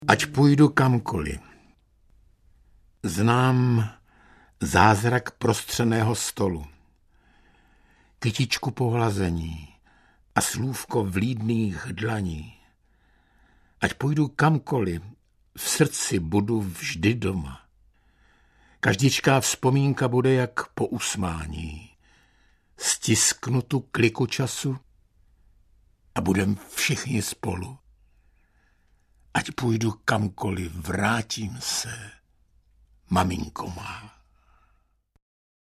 Buďme blaženi audiokniha
Buďme blaženi - básně Františka Novotného v podání Luďka Munzara.
Ukázka z knihy